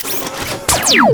larkanstungun.wav